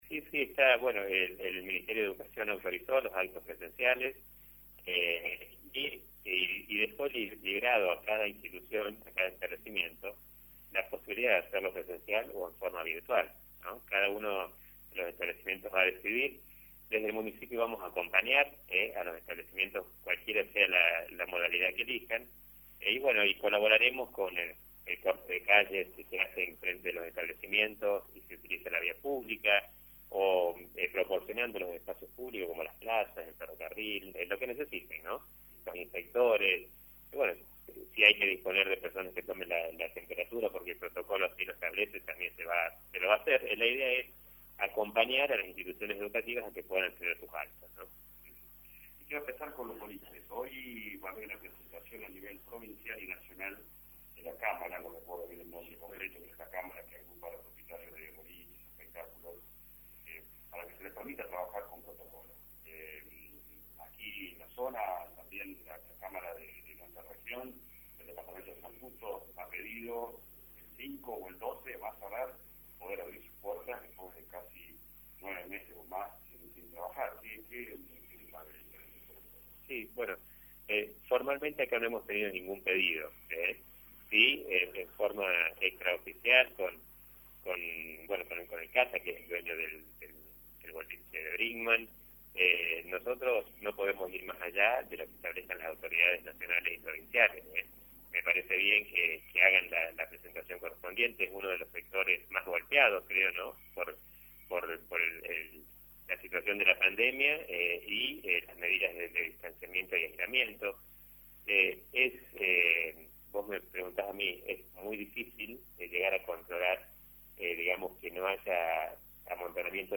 (🔊 ) – Tevez habló de Viviendas, Plan Habitar, Viviendas Semillas, finanzas municipales y apertura de boliches